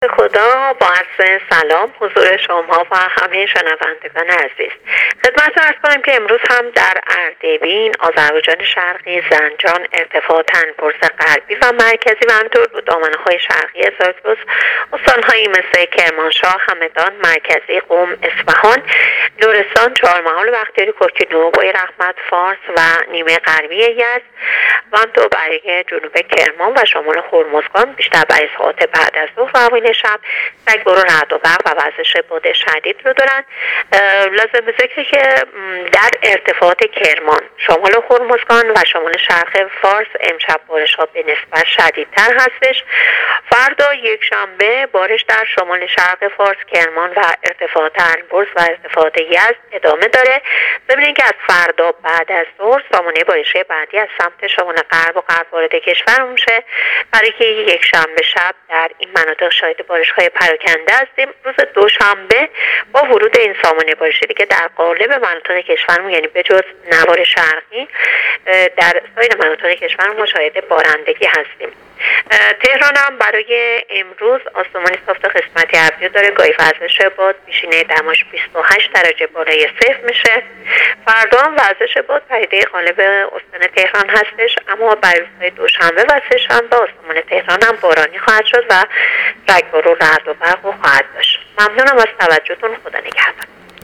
گزارش رادیو اینترنتی پایگاه‌ خبری از آخرین وضعیت آب‌وهوای یکم اردیبهشت؛